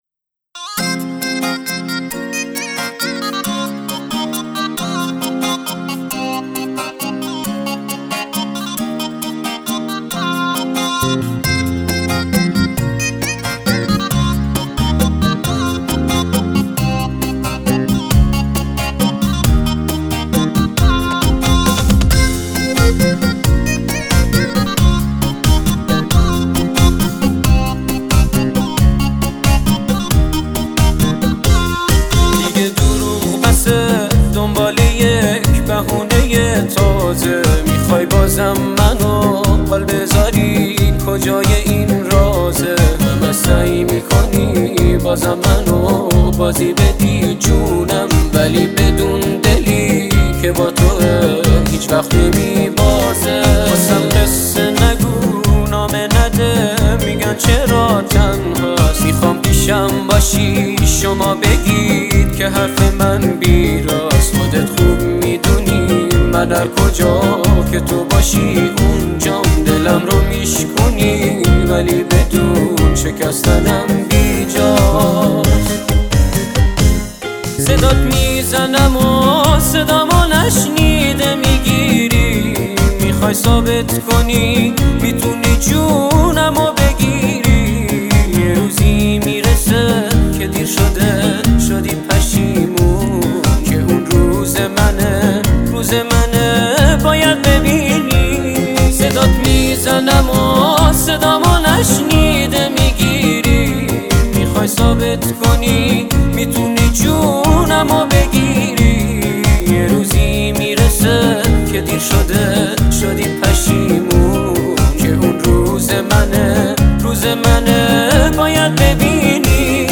ضبط : استودیو عدنان